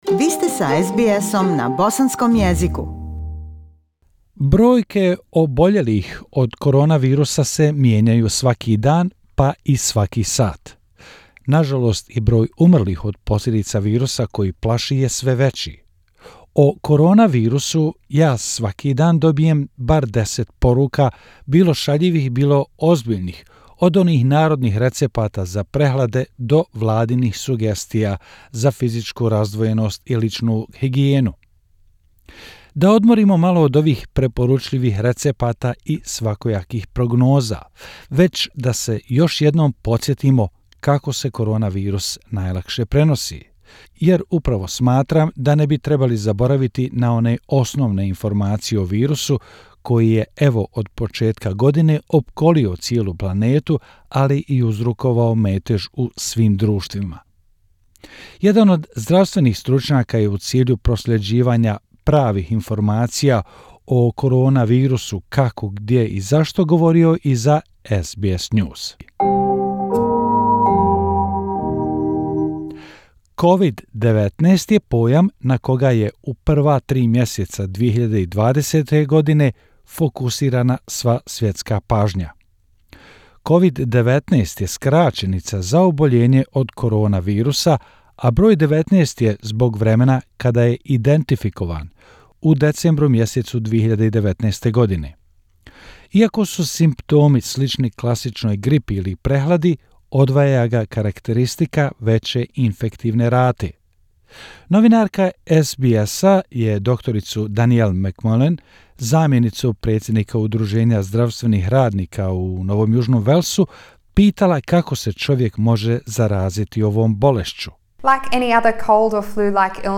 It’s the only topic of conversation at the moment but the most basic facts about COVID-19, the disease caused by the new coronavirus, are a source of some confusion. SBS news spoke to an expert to get the right information.